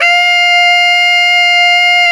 Index of /90_sSampleCDs/Roland L-CD702/VOL-2/SAX_Tenor mf&ff/SAX_Tenor ff
SAX TENORF0V.wav